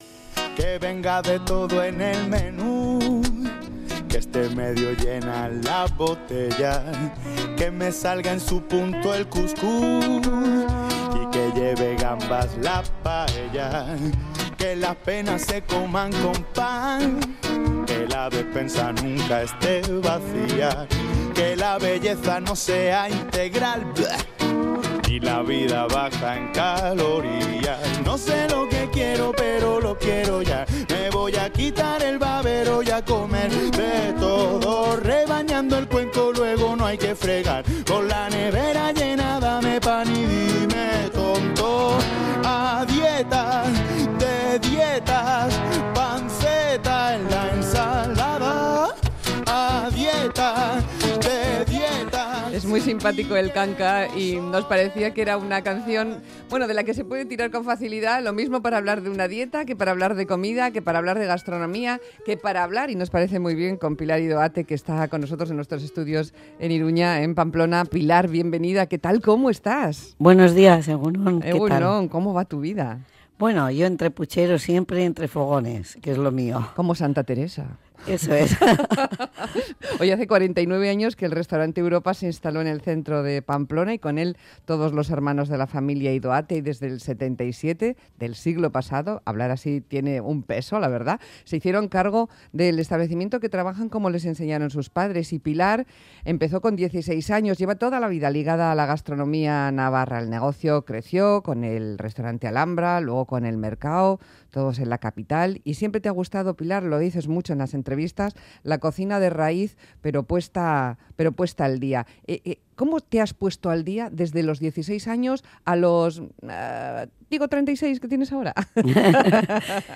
Entrevista en Radio Euskadi